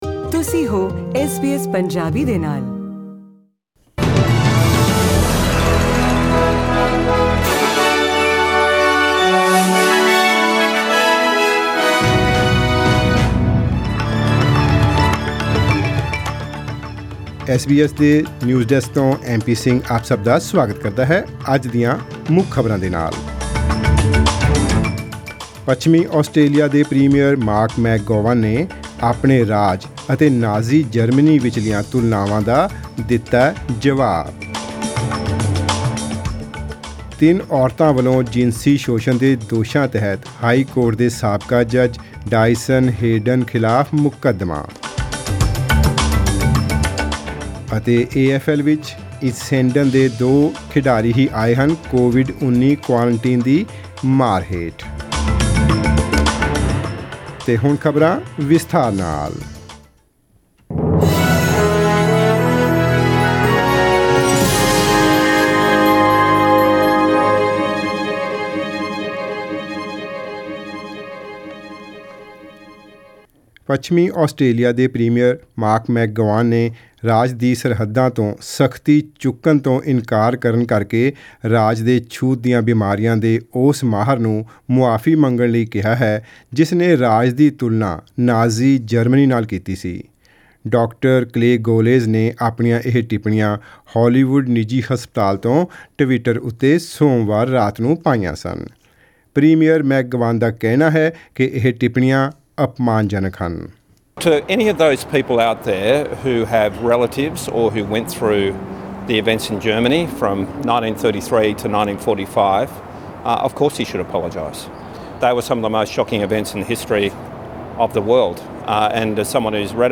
Australian News in Punjabi: 23 June 2020